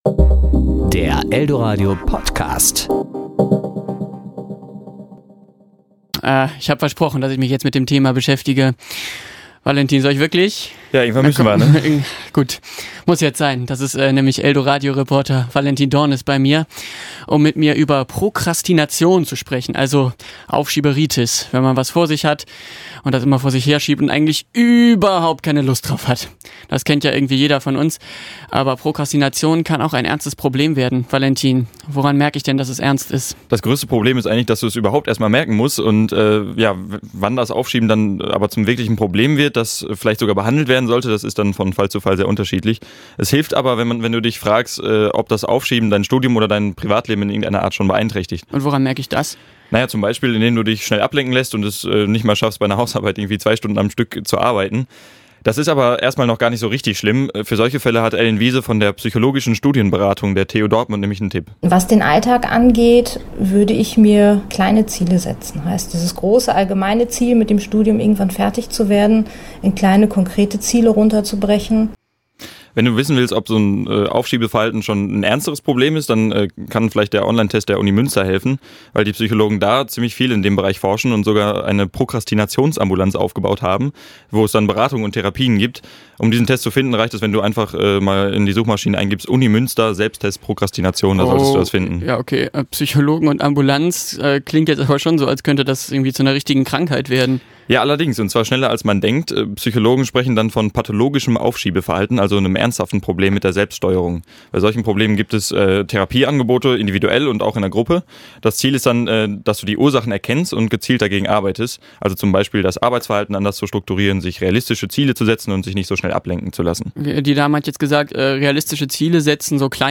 Serie: Kollegengespräch  Sendung: Toaster